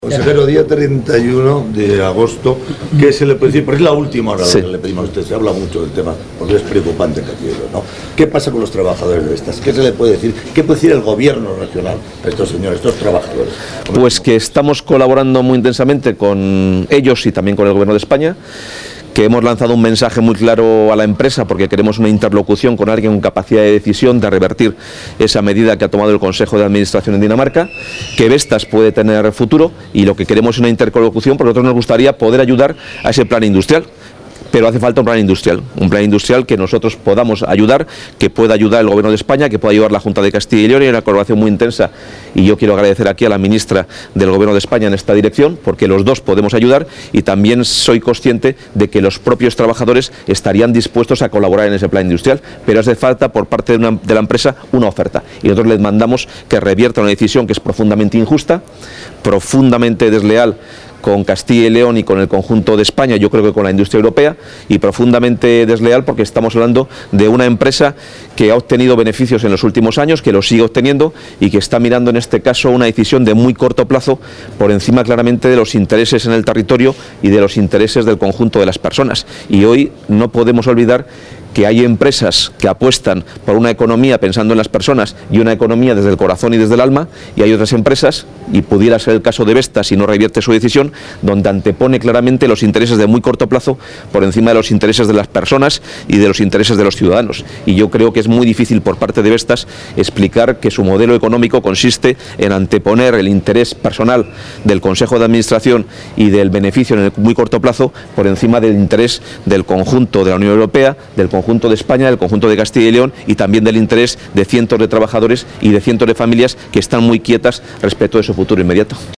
Declaraciones del consejero de Empleo.